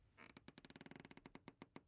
吱吱声
描述：简单的吱吱声。用变焦h4n记录。
标签： 吱吱作响 椅子
声道立体声